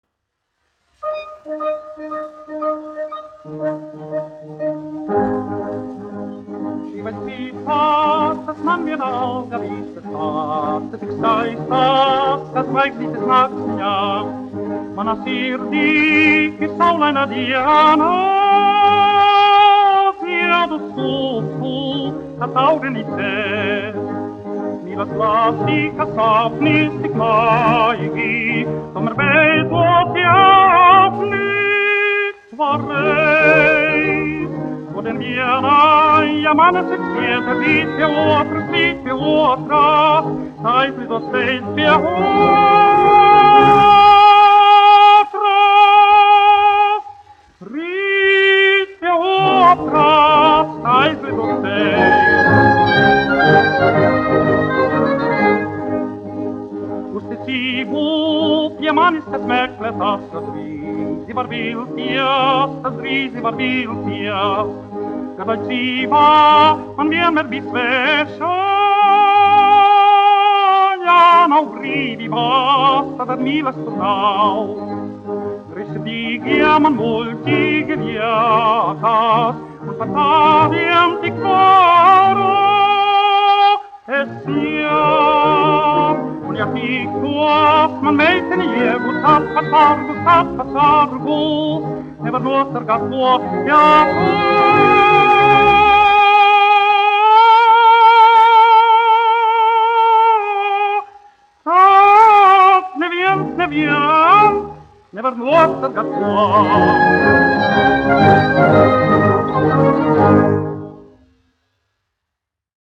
Latvijas Nacionālās operas orķestris, izpildītājs
1 skpl. : analogs, 78 apgr/min, mono ; 25 cm
Operas--Fragmenti
Skaņuplate